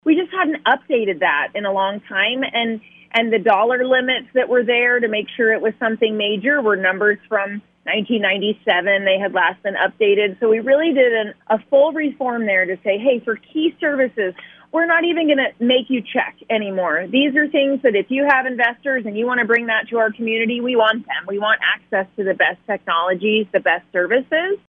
Kara Warme, Iowa State Senator in District 26, joined the KFJB line on Friday to discuss HF2635 working its way through the Iowa Legislature.